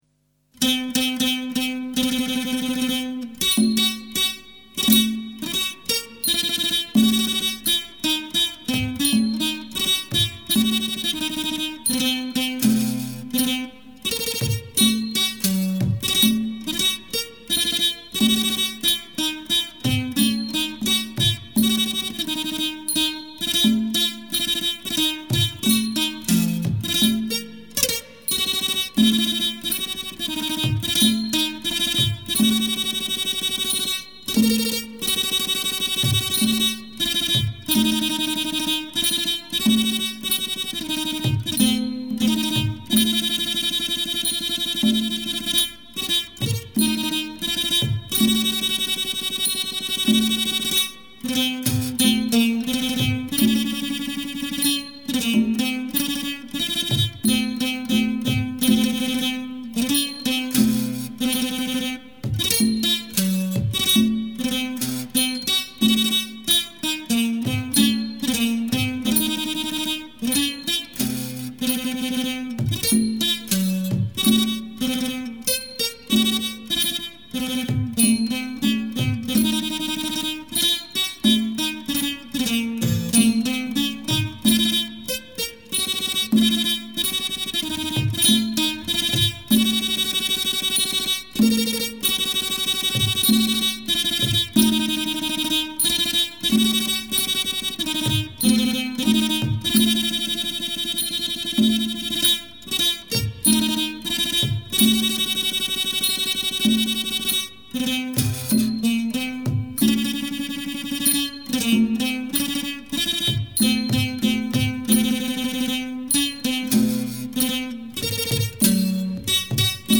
[3/5/2008]Thai Classical Music (Instrumental)
Thai Classical Music